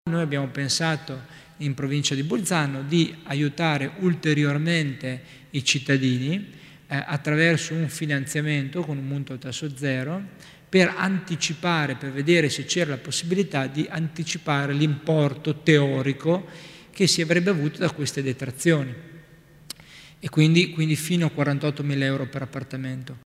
Il Vicepresidente Tommasini spiega le novità di tema di agevolazioni per le ristrutturazioni